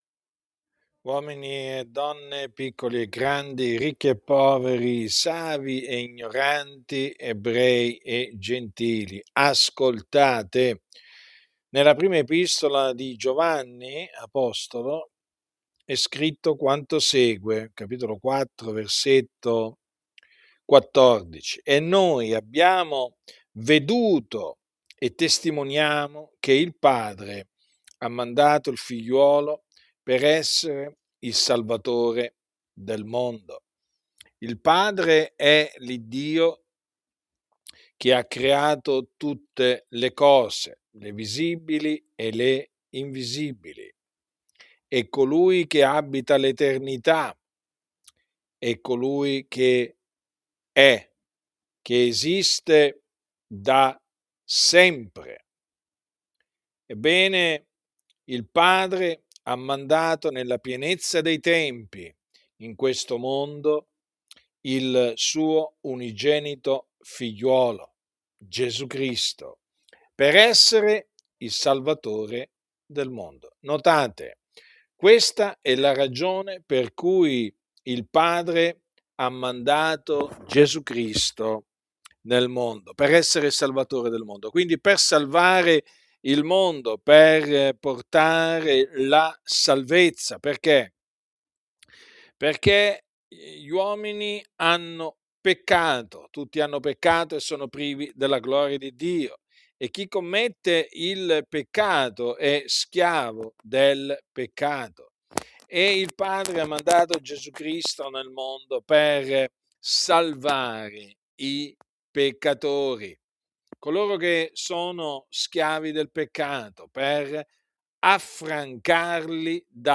Archivio delle predicazioni